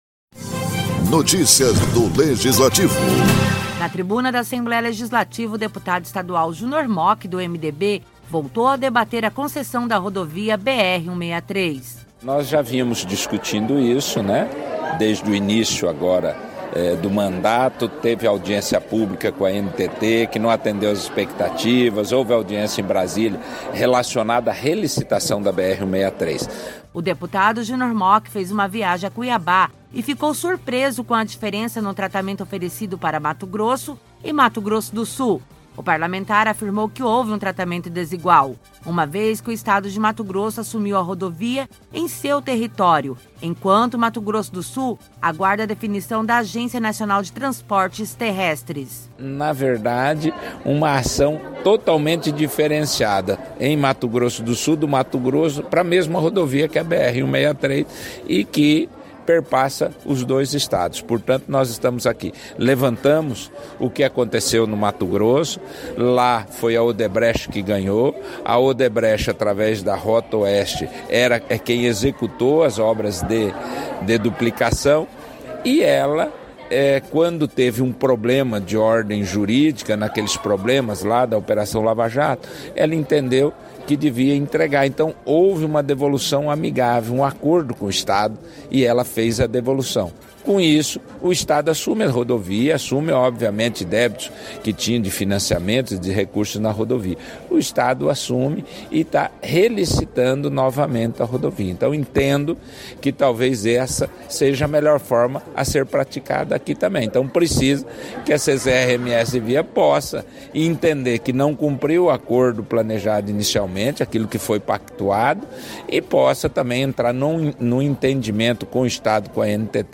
Na tribuna durante a sessão ordinária o parlamentar afirmou que houve um tratamento desigual, uma vez que Mato Grosso assumiu a da rodovia em seu território, enquanto Mato Grosso do Sul aguarda definição da Agência Nacional de Transportes Terrestres (ANTT).